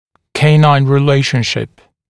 [‘keɪnaɪn rɪ’leɪʃnʃɪp][‘кейнайн ри’лэйшншип]соотношение клыков